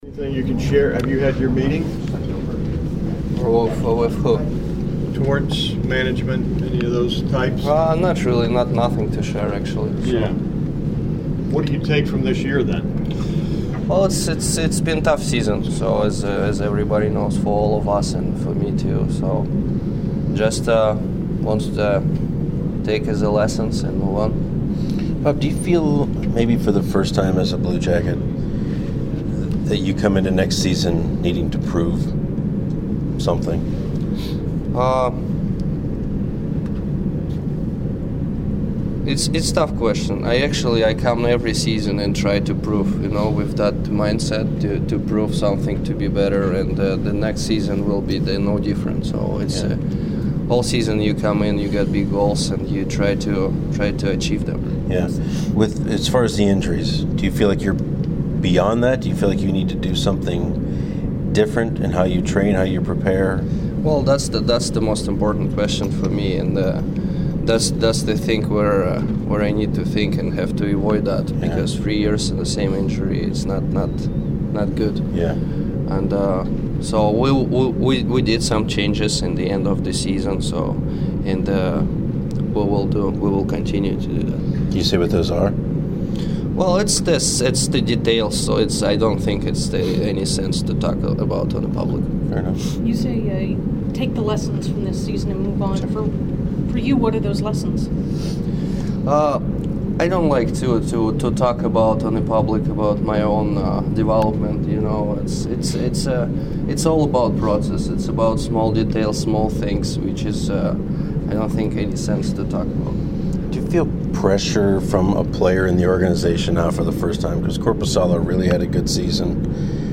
2016 Exit Interviews - Bobrovsky